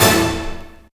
68_05_stabhit-A.wav